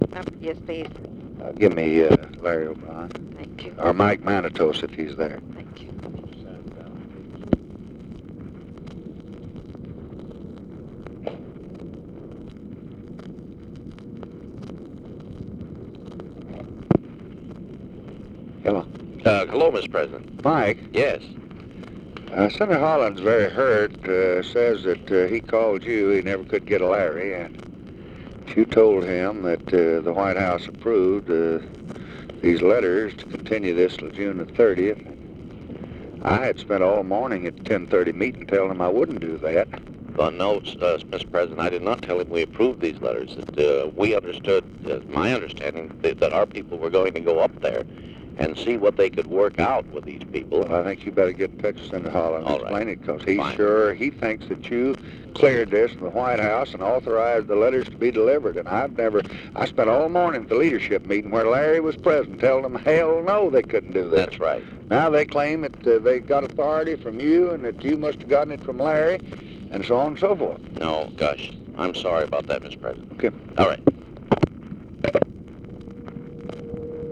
Conversation with MIKE MANATOS, February 10, 1965
Secret White House Tapes